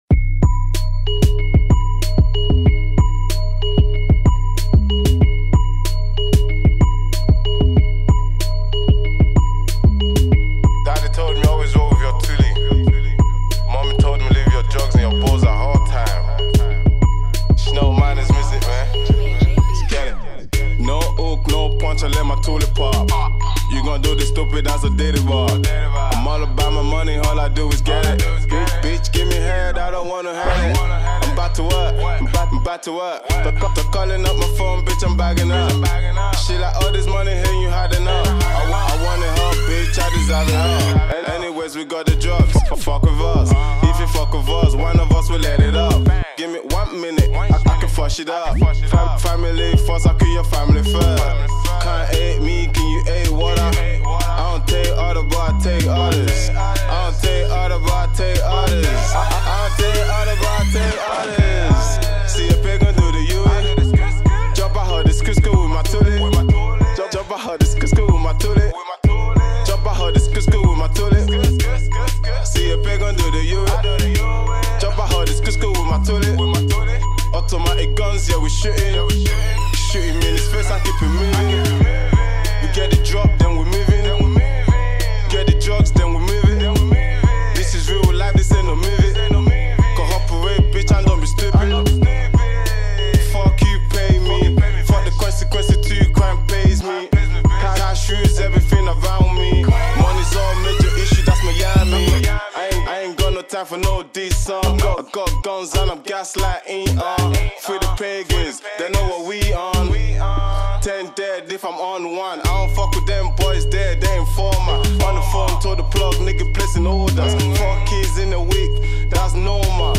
a trap infused song.